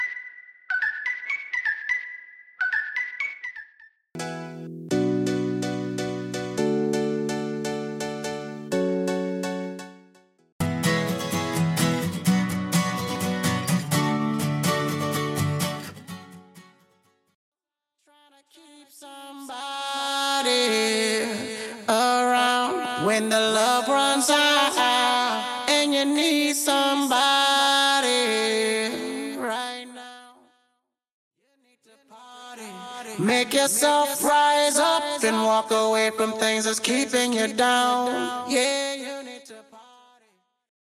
Studio Whistle Stem
Studio Pads, Wawes, Keys & Synths Stem
Studio Guitar Stem
Studio Bassline Stem